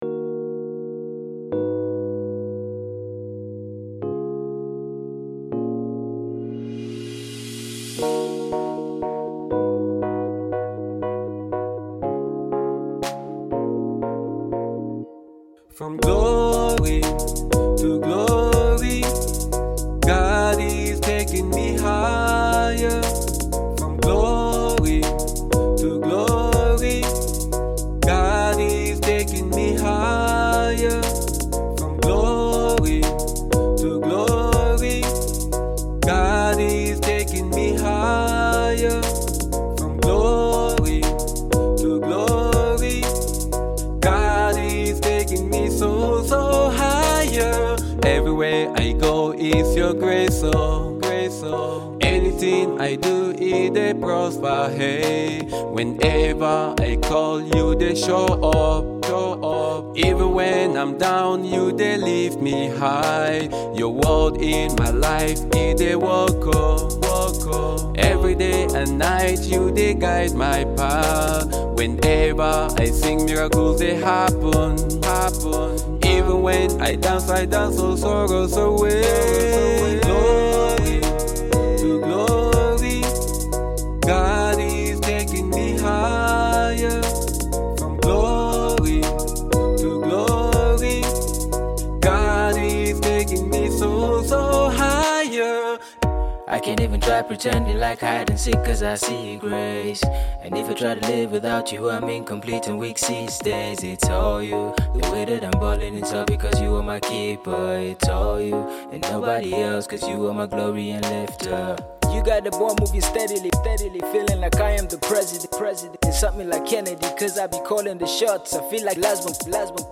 Nigerian Gospel singer and songwriter